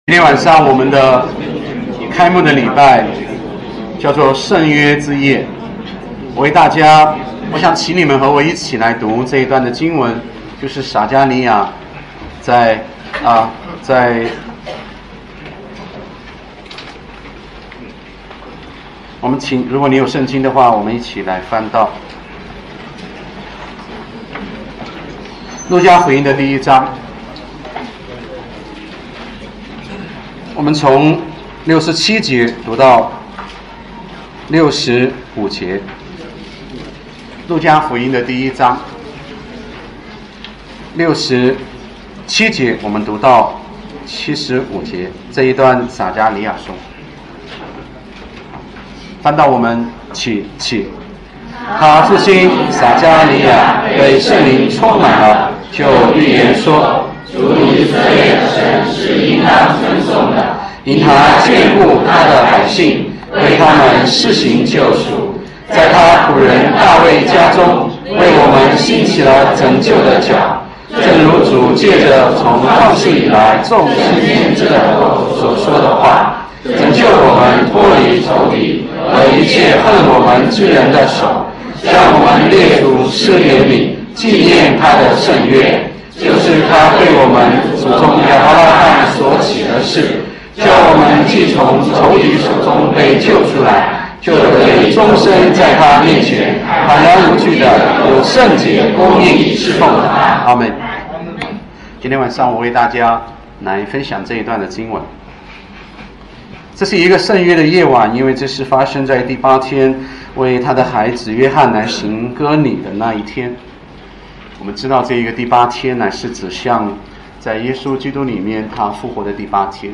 神学论坛讲道精选